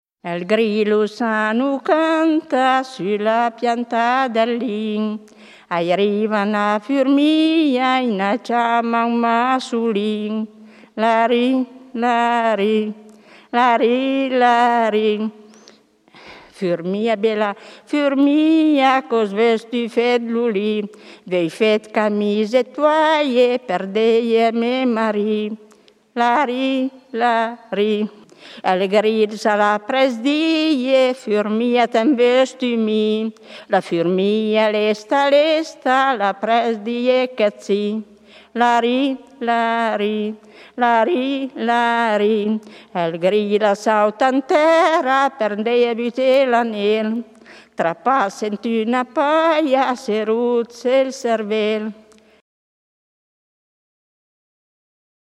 registrata a Cascina del Pozzo, Cortazzone (AT), nel 1966